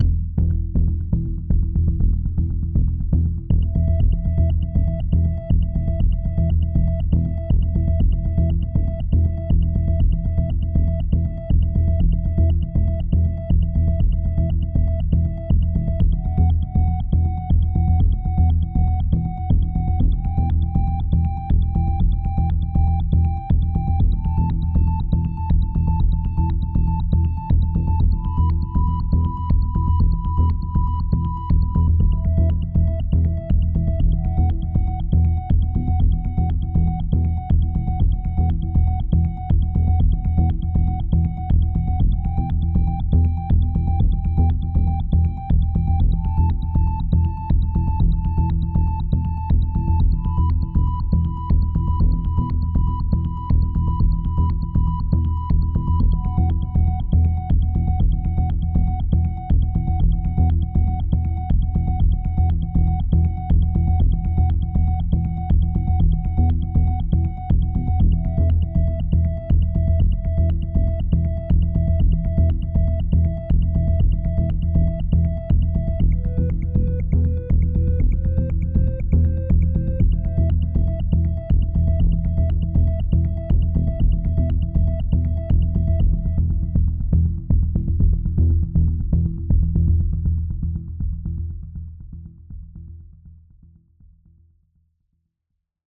Ambient music pack